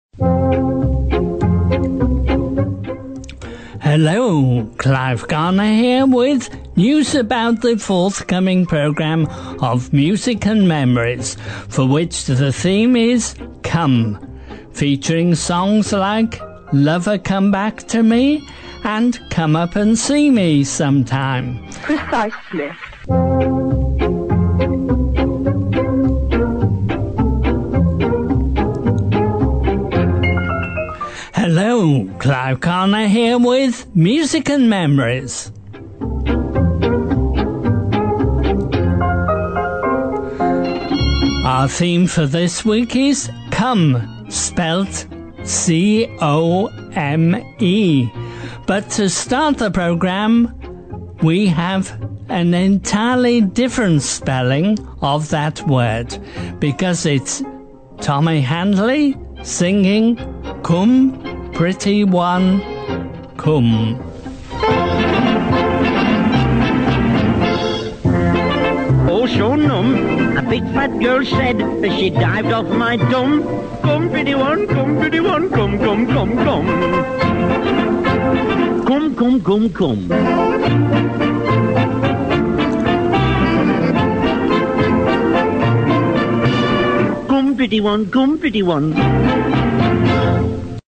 Here's a golden classic trailer from BBC Local Radio.
At some stage the producer has a quiet word with him.